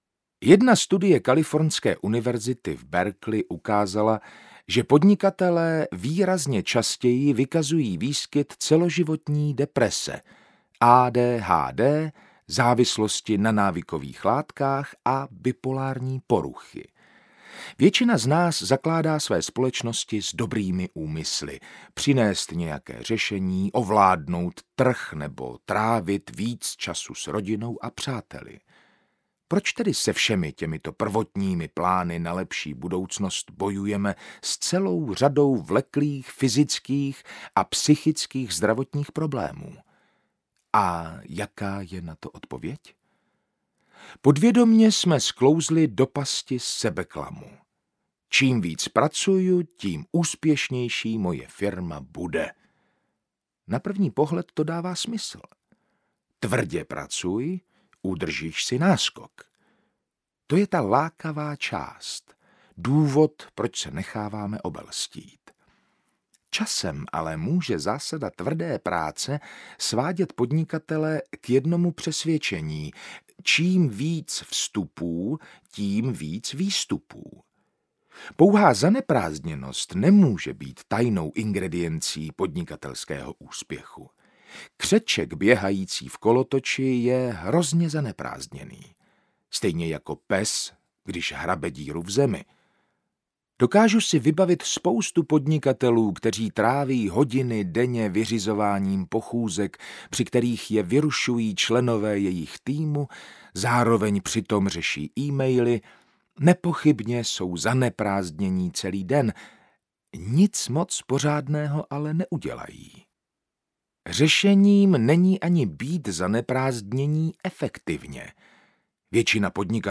Poslechnout delší ukázku
audiokniha